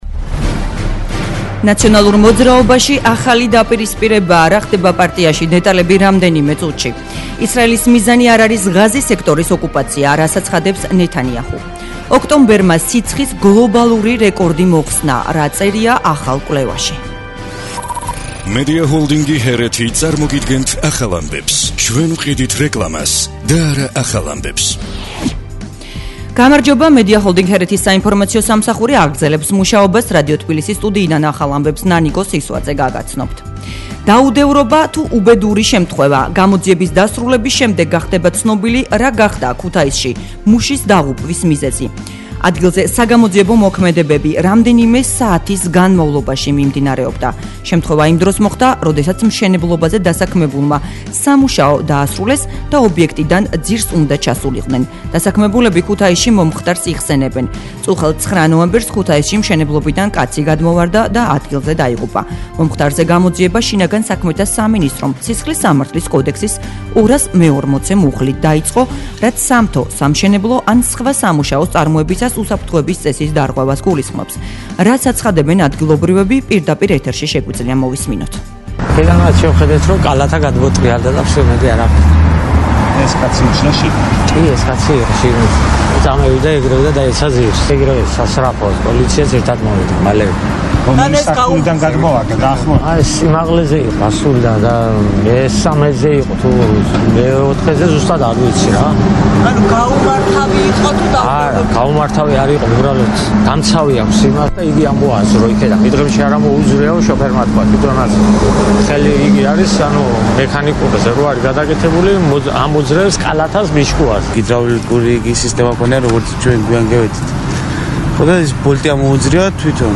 ახალი ამბები 14:00 საათზე